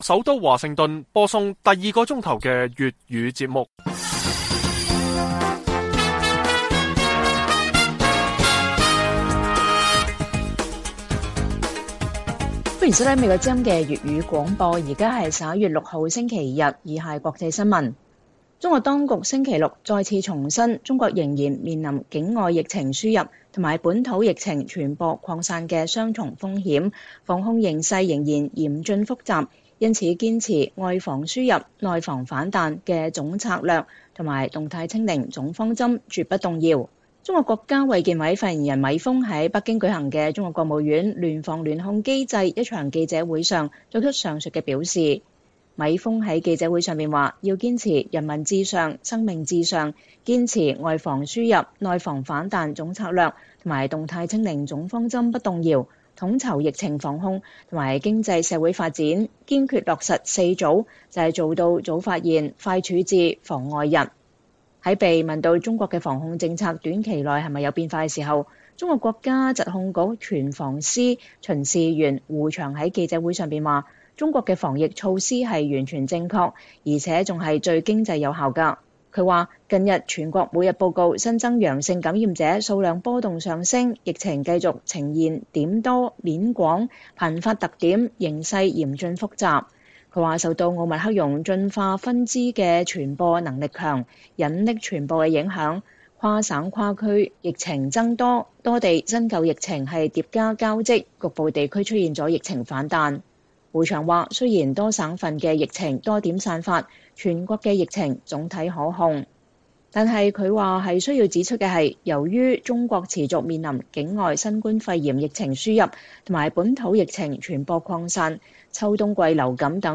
粵語新聞 晚上10-11點: 中國當局重申“動態清零”防疫方針不動搖 撲殺放鬆傳言？